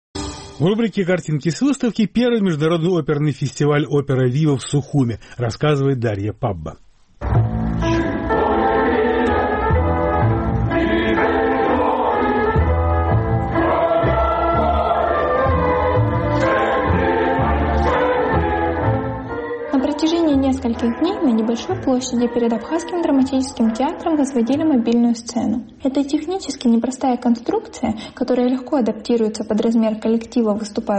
Первый Международный оперный фестиваль «Opera Viva» состоялся в Сухуме в прошлую пятницу, 9 июля.